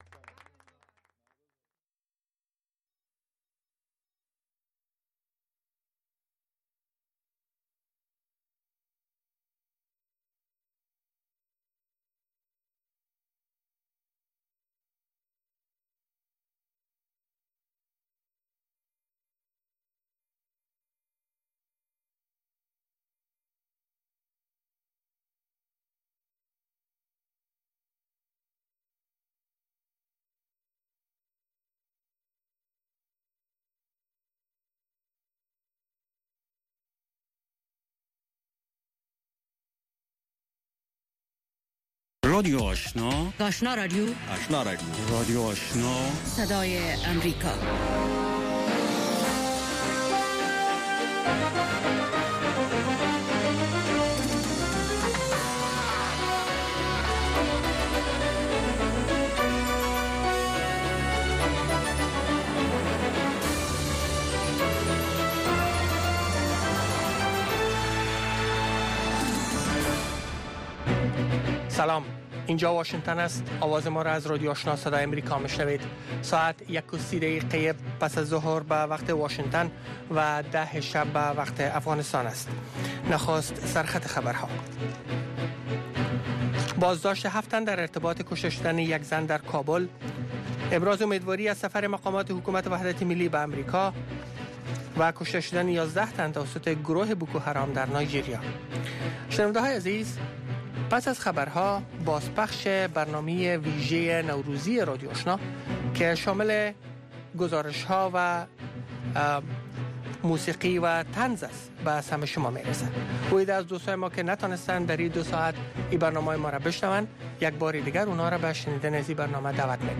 برنامه گفت و شنود/خبری اتری - گفتمان مشترک شما با آگاهان، مقام ها و کارشناس ها.